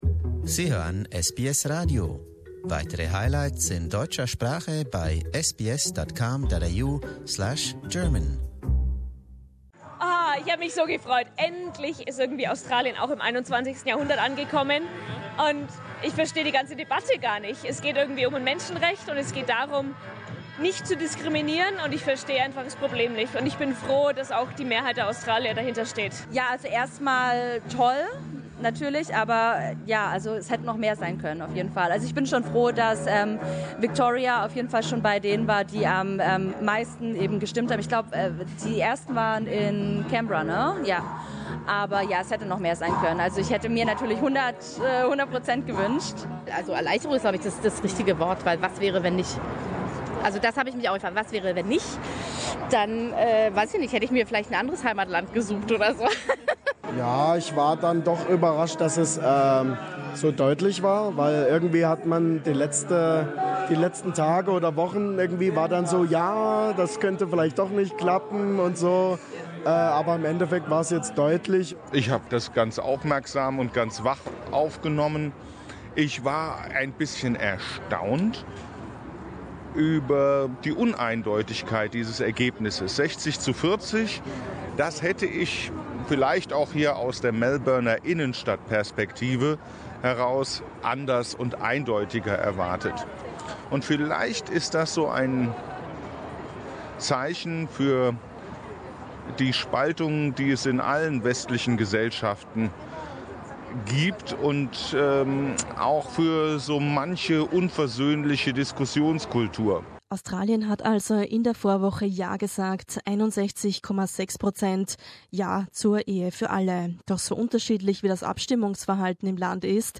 Wie sehen es Mitglieder der deutschen Gemeinde? Wir haben Besucher des German Cinemas in Melbourne befragt, unter ihnen ein Pastor und eine in einer gleichgeschlechtlichen Partnerschaft lebende Mutter zweier Kinder.